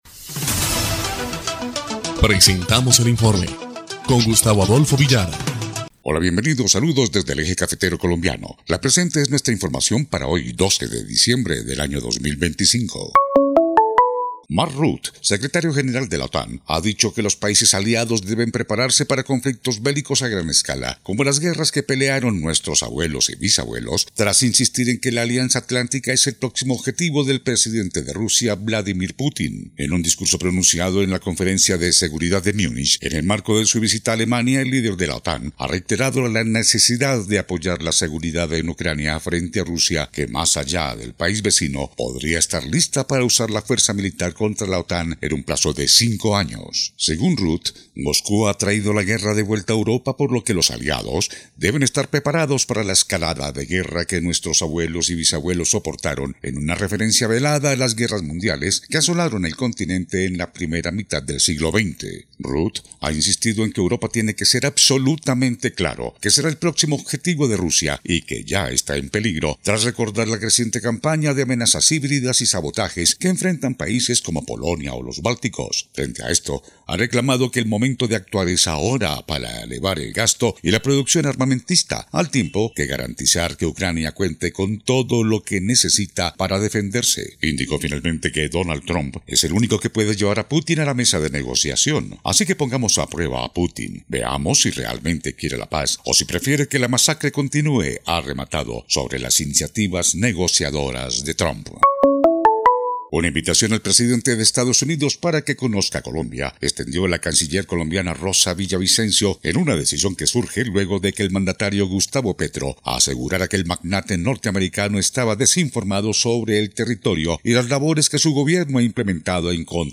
EL INFORME 3° Clip de Noticias del 12 de diciembre de 2025